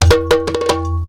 PERC 07.AI.wav